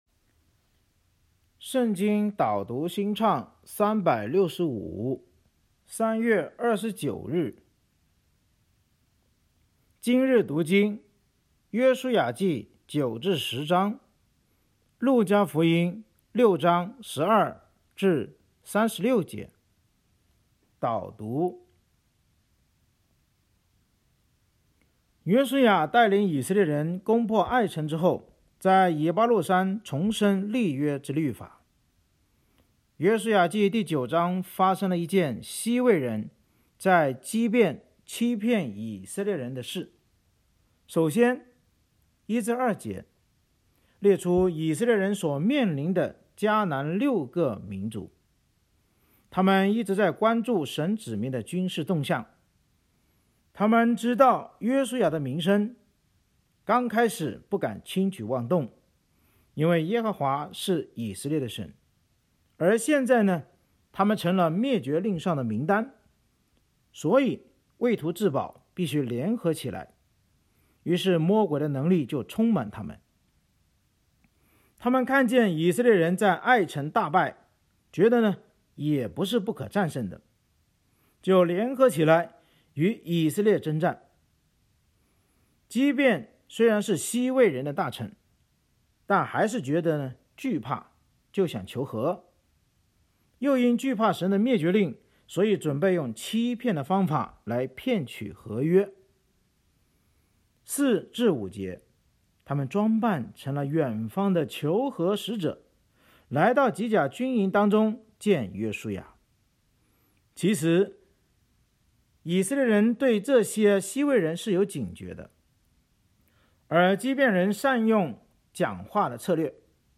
圣经导读&经文朗读 – 03月29日（音频+文字+新歌）